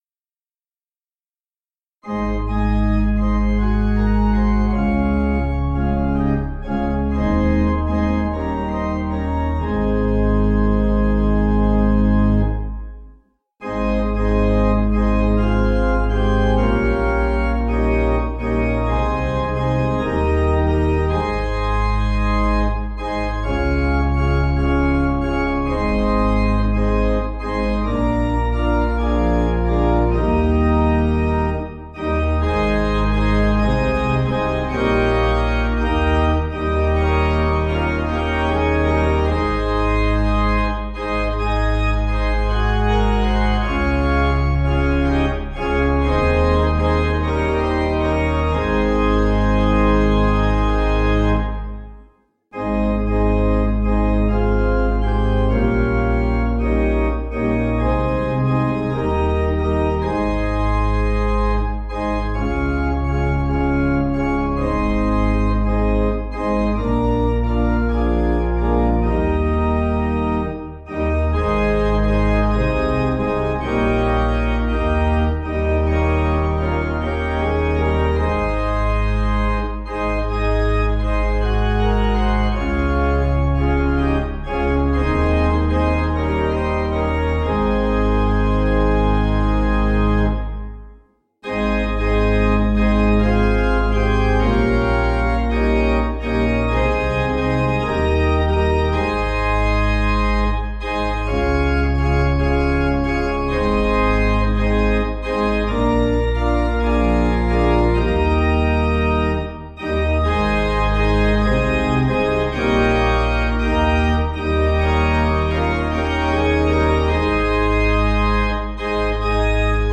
Organ
(CM)   3/Ab